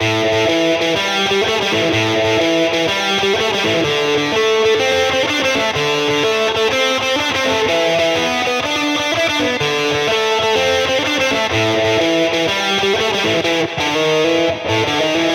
摇滚蓝调
描述：老式的东西
Tag: 125 bpm Blues Loops Guitar Electric Loops 2.58 MB wav Key : Unknown